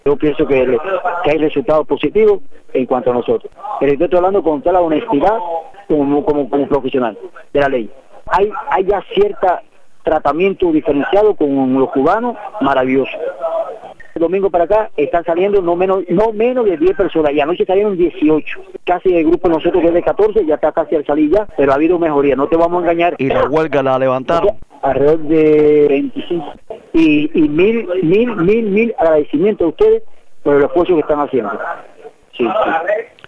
dijo por teléfono desde Siglo 21
Declaraciones